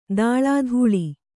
♪ dāḷādhūḷi